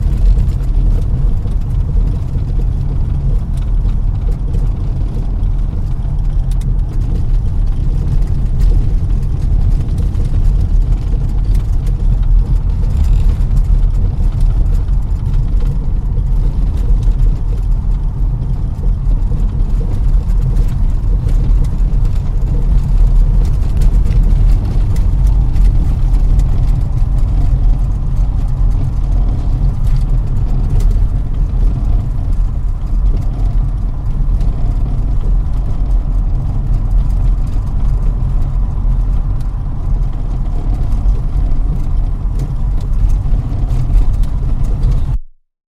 Звуки шоссе, дорог
Шум трения колес по шоссе в салоне автомобиля